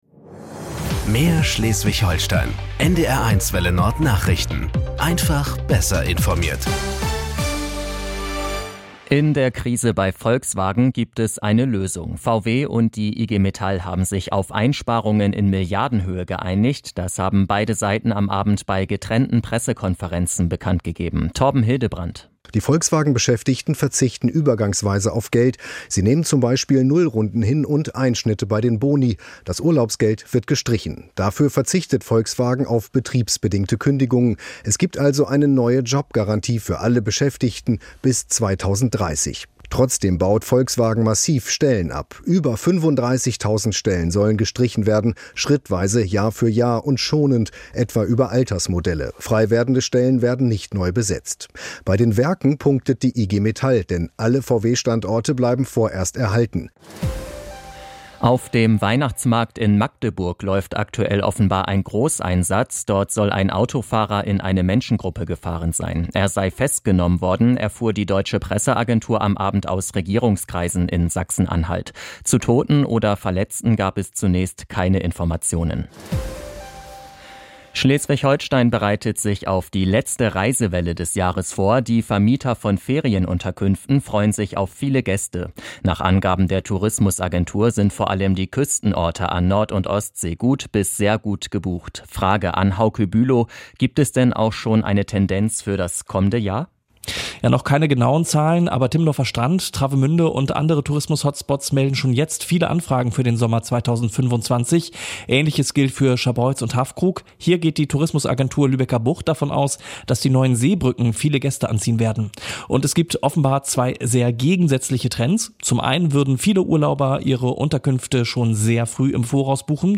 Nachrichten 20:00 Uhr